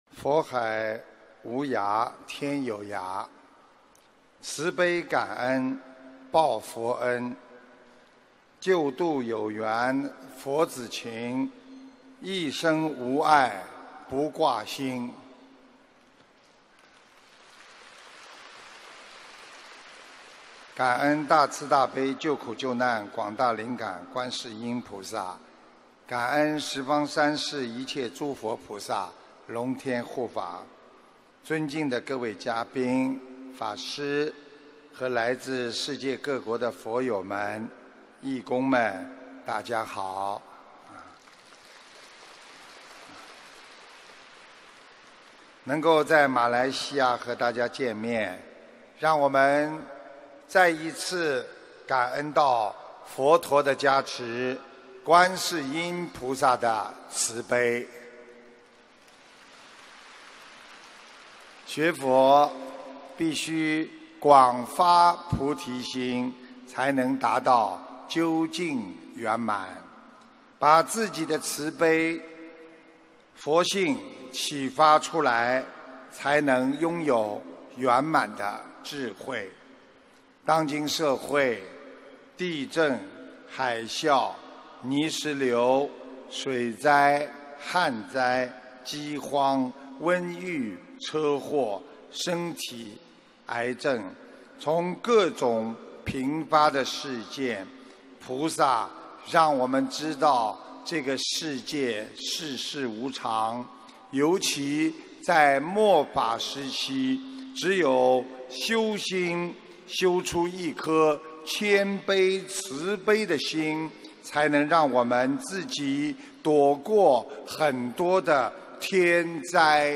心灵甘露»心灵甘露 遍照法界 菩提慧光 法会开示 视频:791.佛海无涯天有涯，慈悲感恩报佛恩，救度有缘佛 ...